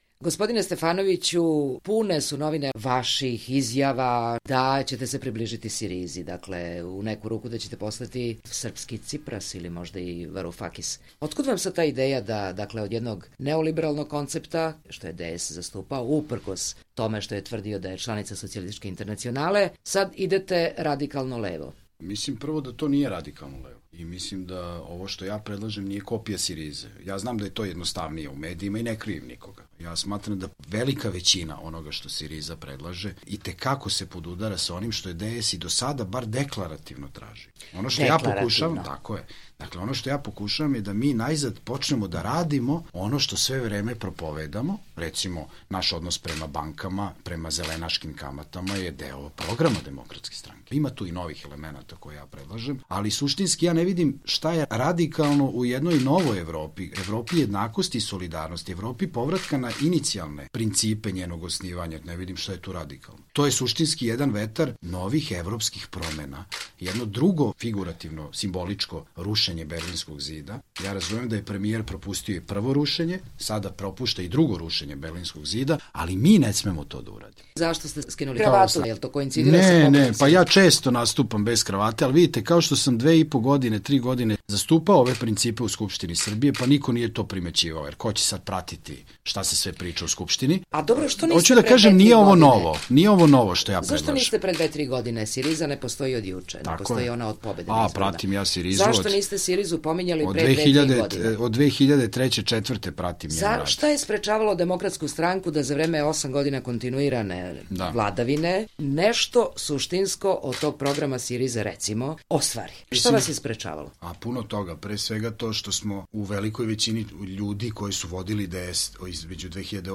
Intervju nedelje - Borislav Stefanović